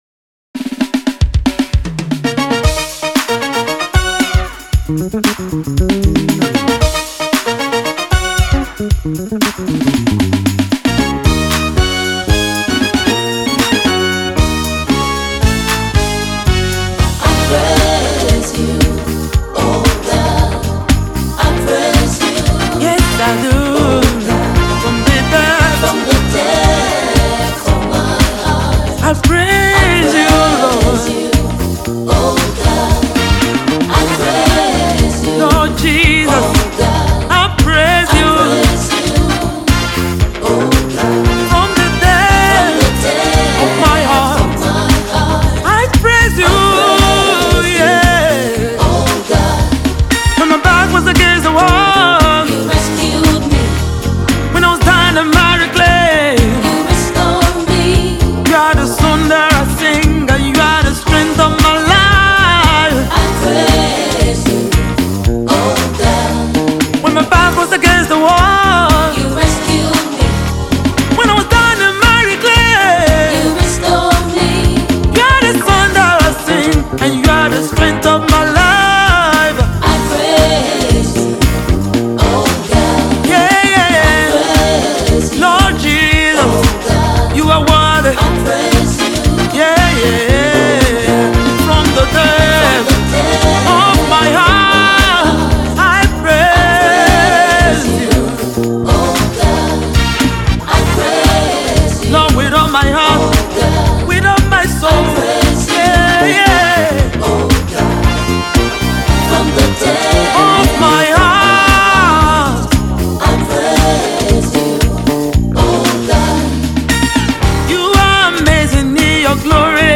intense heartfelt praise
gospel music